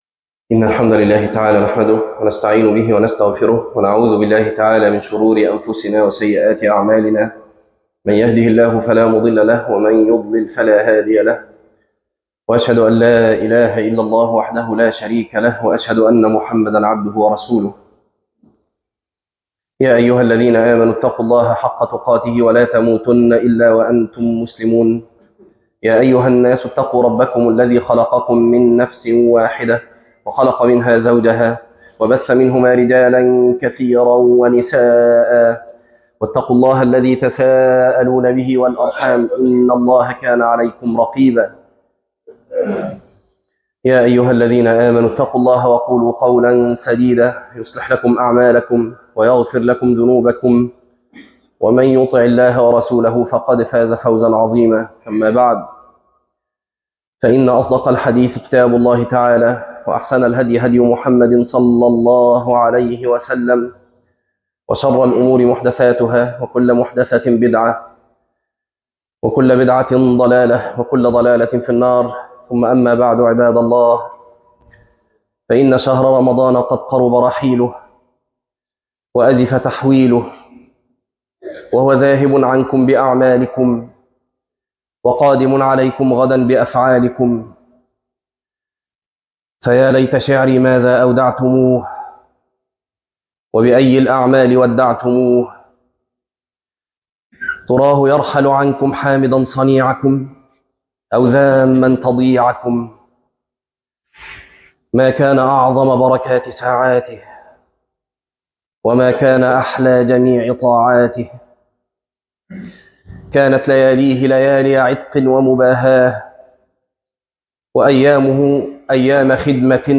تفاصيل المادة عنوان المادة تأملات في سورة القدر - خطبة تاريخ التحميل الأثنين 13 ابريل 2026 مـ حجم المادة 11.37 ميجا بايت عدد الزيارات 13 زيارة عدد مرات الحفظ 6 مرة إستماع المادة حفظ المادة اضف تعليقك أرسل لصديق